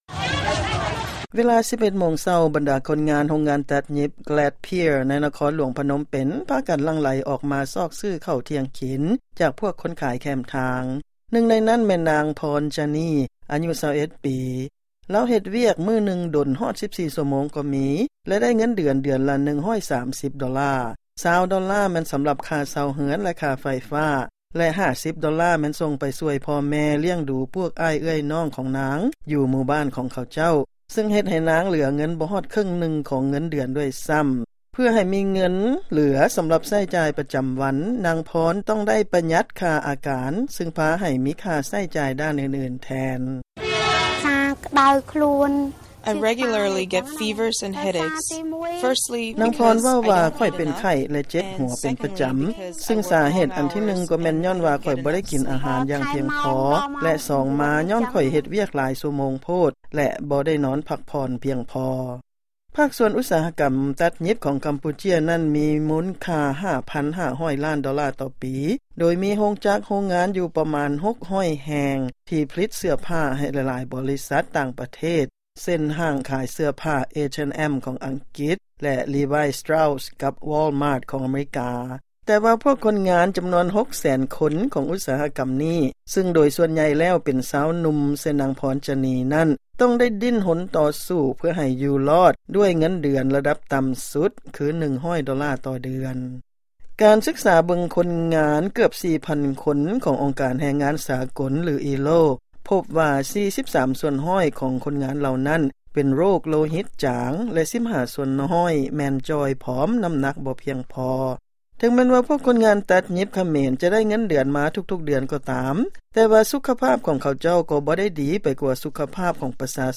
ລາຍງານເລື້ອງ ໂຄງການທົດລອງ ປັບປຸງໂພຊະນາການ ຂອງຄົນງານຕັດຫຍິບຂະເໝນ